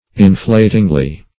inflatingly - definition of inflatingly - synonyms, pronunciation, spelling from Free Dictionary Search Result for " inflatingly" : The Collaborative International Dictionary of English v.0.48: Inflatingly \In*flat"ing*ly\, adv. In a manner tending to inflate.